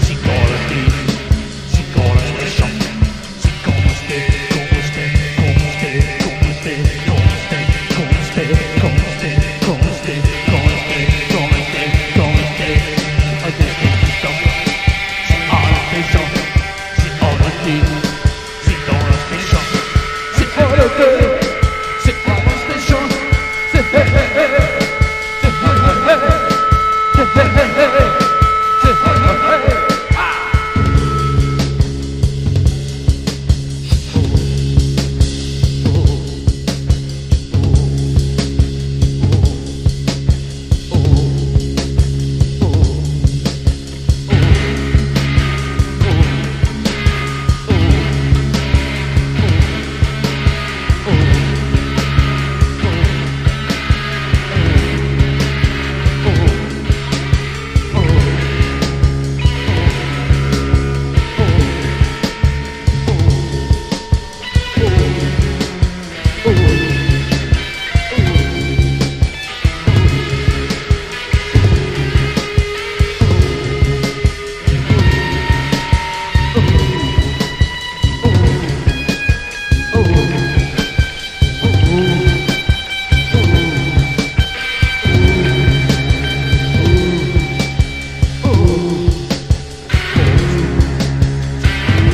英国カルト・ポストパンク/エクスペリメンタル・バンド唯一のフル・アルバム！